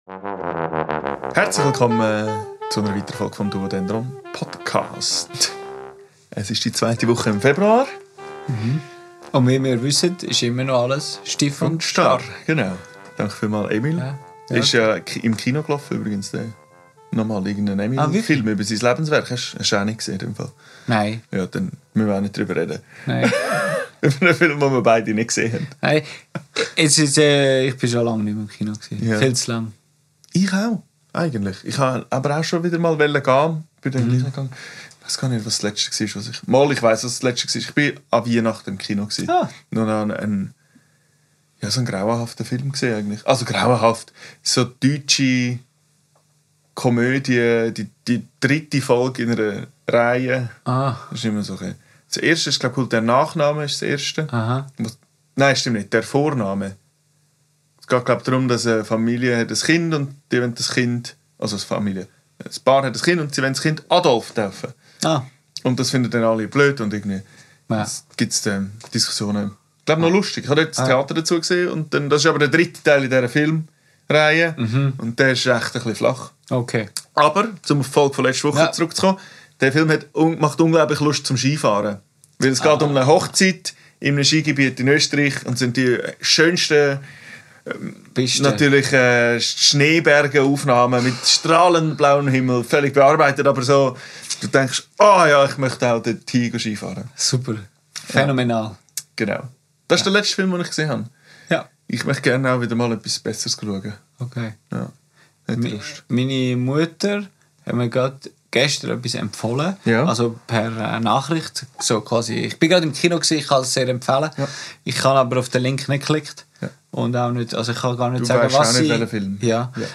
Ein Appell ans Einander-Dinge-Vorschlagen, eine Improvisation wie ein Gespräch und eine Unterhaltung darüber, was für Gesprächsformen es eigentlich noch so gibt, bevor wir versuchen zwei Geschichten gleichzeitig zu erzählen.